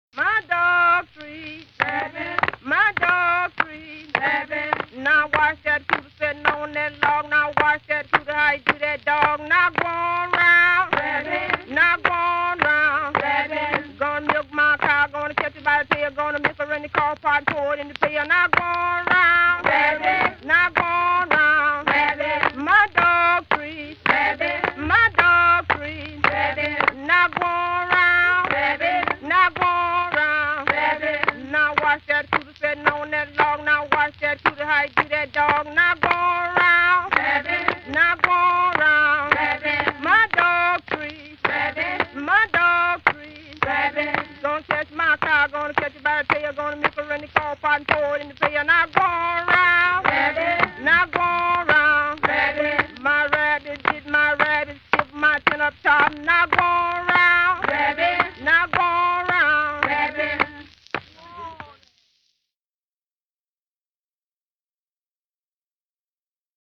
Field recordings paired with these images were recorded in rural Mississippi by John and Alan Lomax between 1934 and 1942.